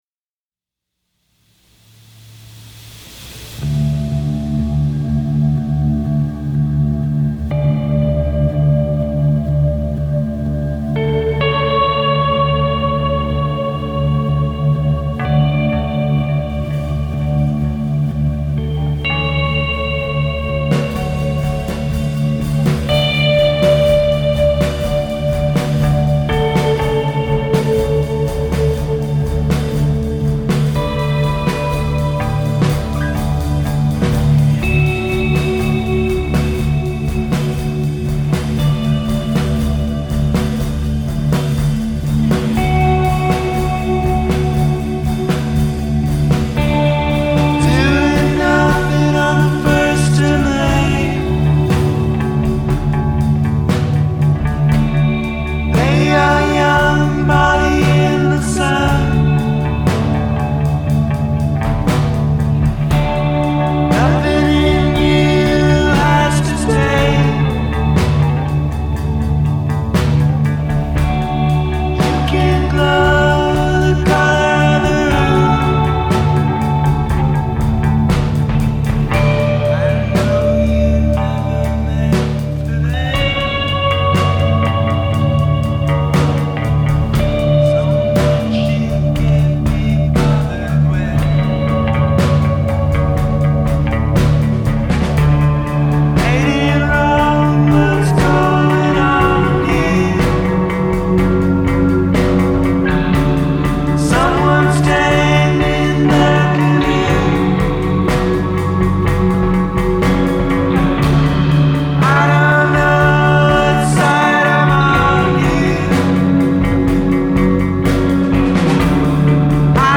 to tape at P.J Mansion in Montreal